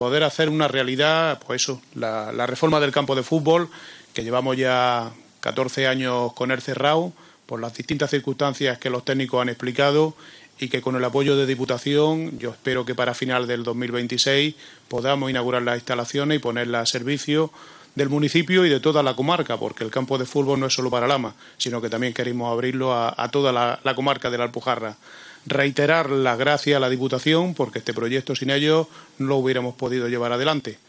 Alcalde-Campo-de-Futbol-.mp3